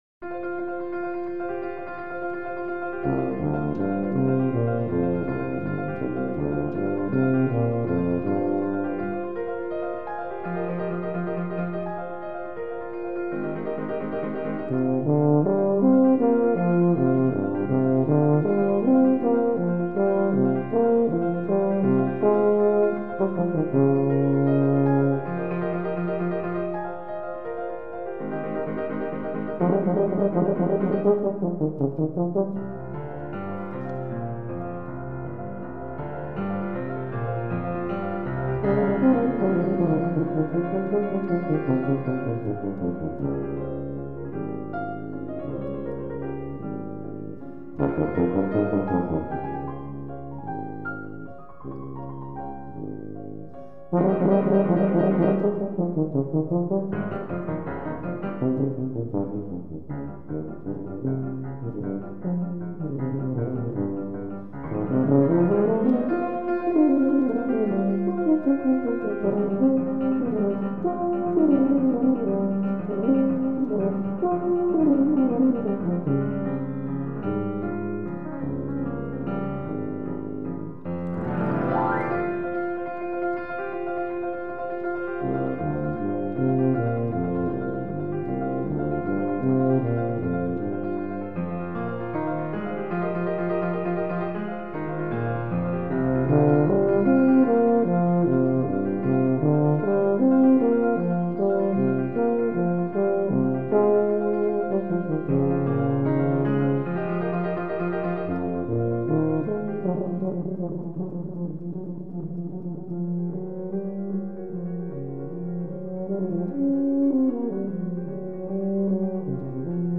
tuba
piano